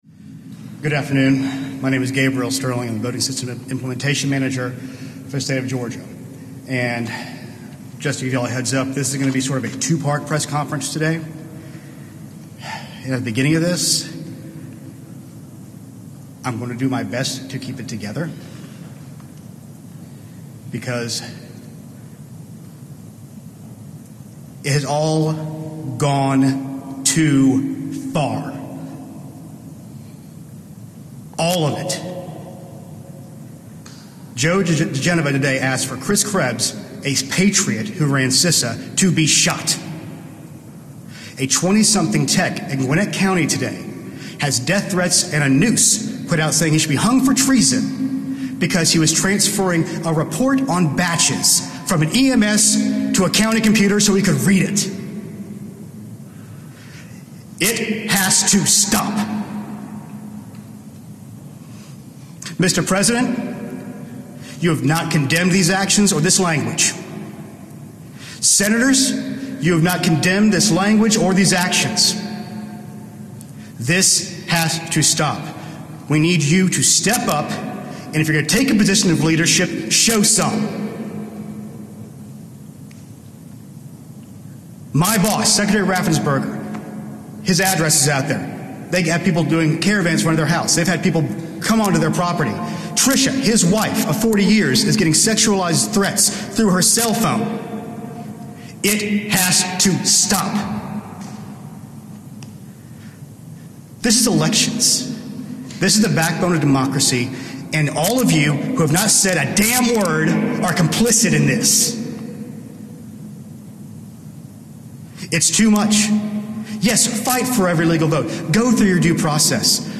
Gabriel Sterling
Audio Note: AR-XE = American Rhetoric Extreme Enhancement